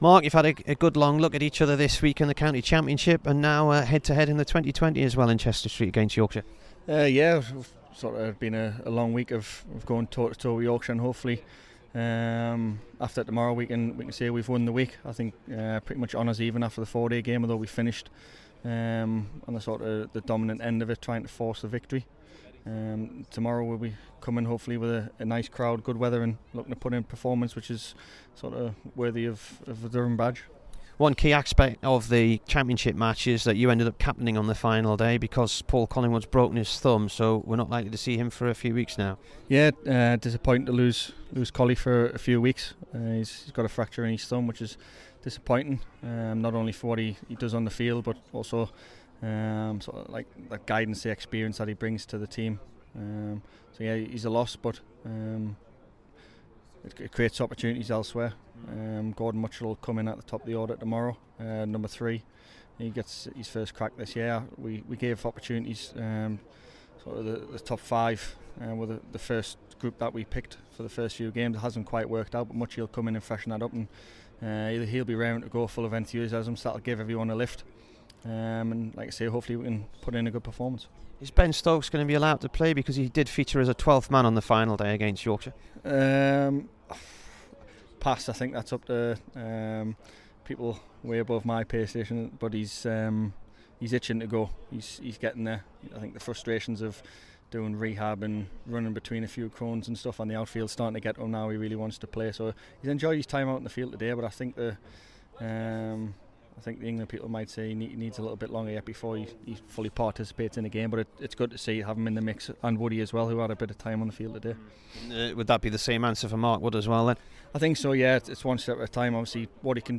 MARK STONEMAN INT